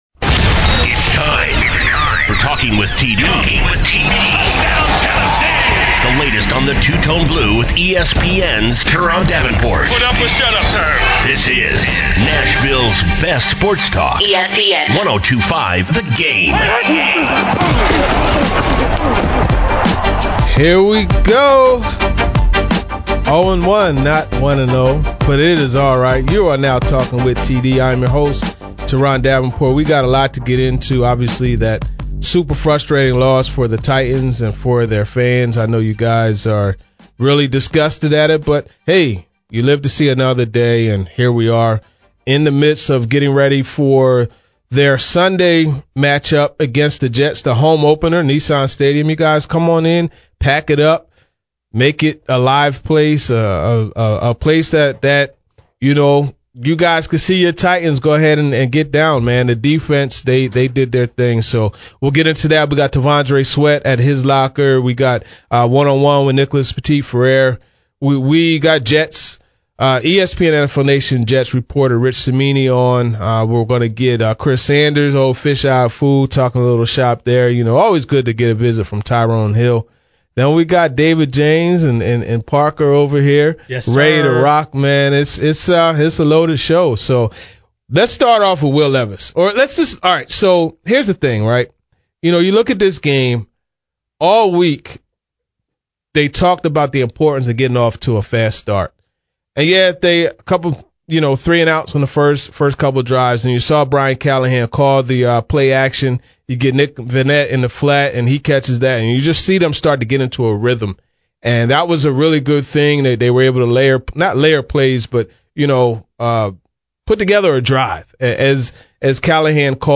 1 on 1 with Nicholas Petit-Frere, T'Vondre Sweat locker scrum